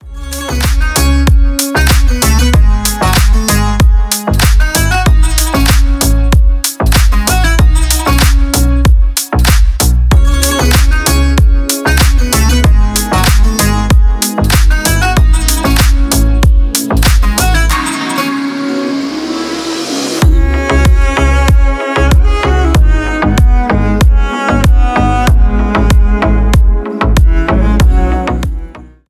deep house , восточные
без слов